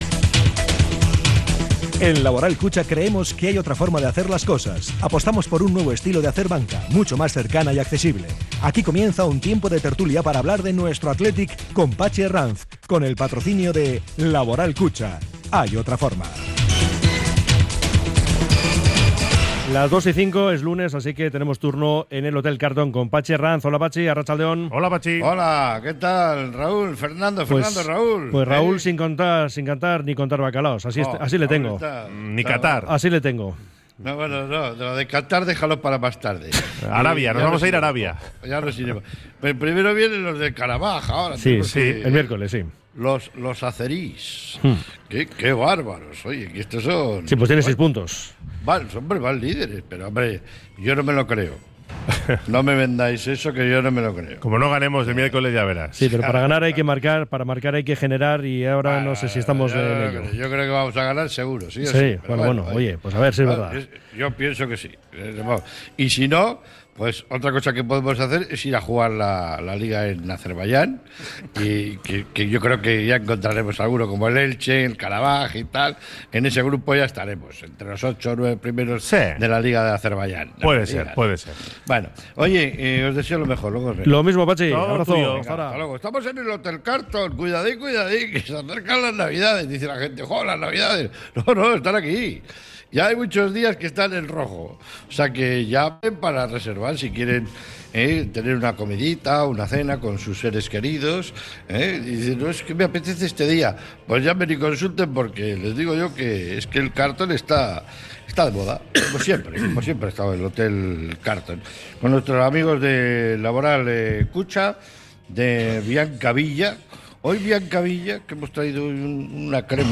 sus invitados desde el Hotel Carlton